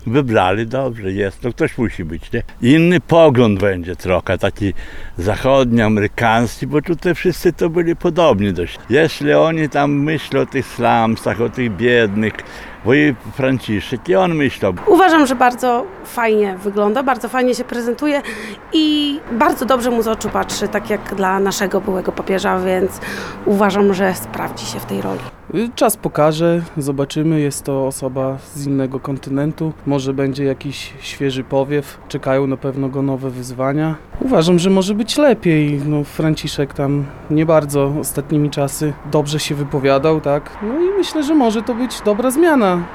– Papież pochodzący z Ameryki może wnieść powiew świeżości i okazać się dobrym zwierzchnikiem Kościoła – uważają przechodnie zapytani na ulicach Suwałk.